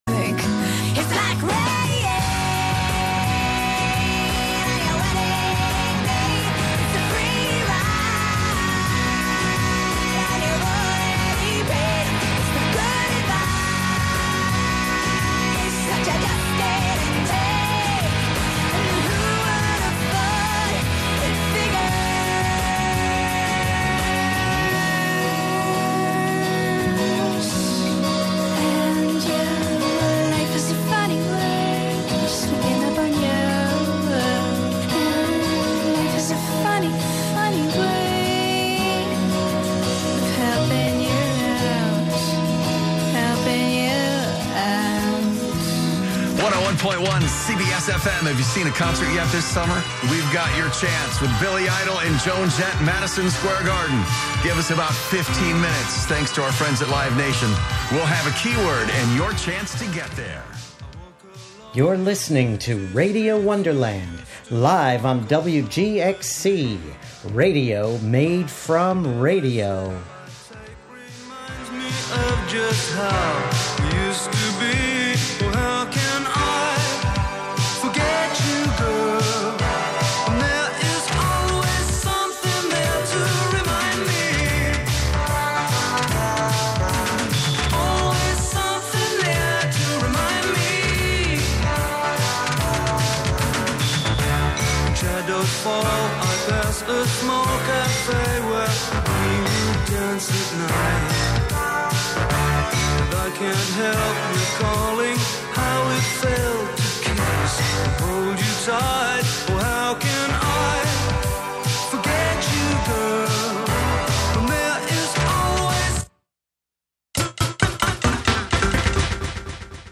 11am Live from Brooklyn, New York
making instant techno 90 percent of the time